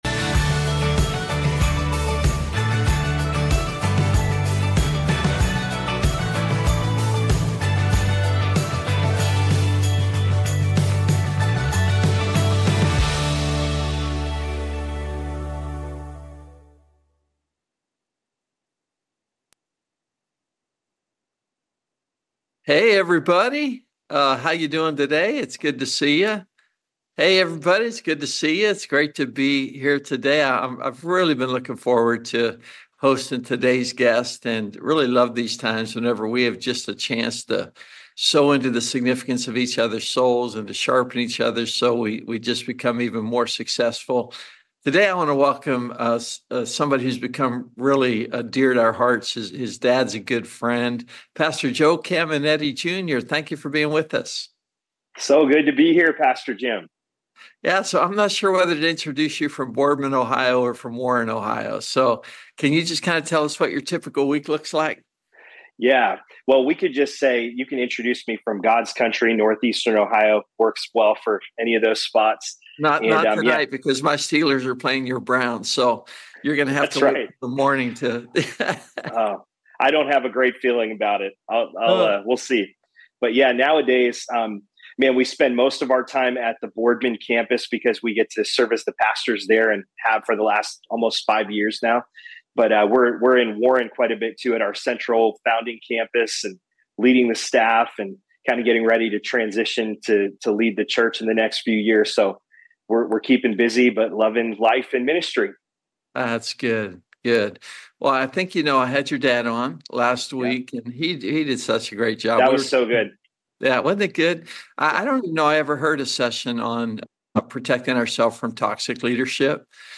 Webinar: Nurturing Trustworthy Leaders - Significant Church Network